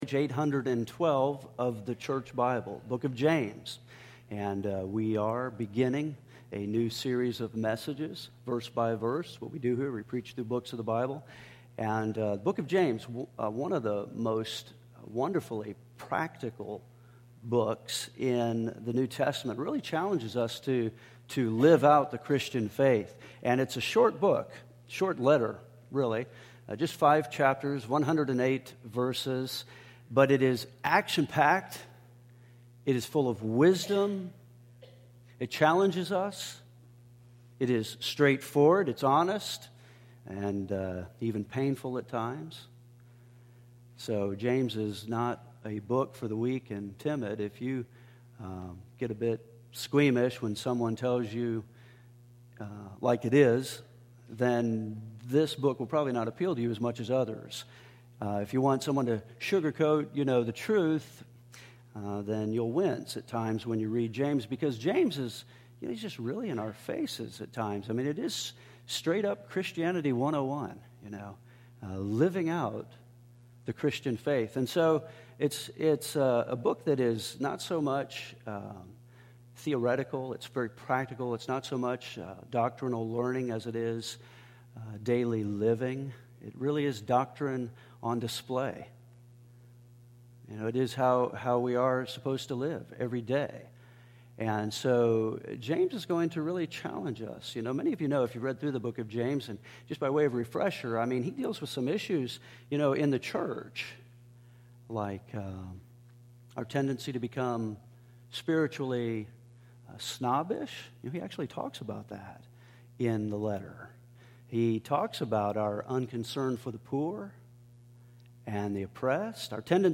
We are beginning our year with a new sermon series.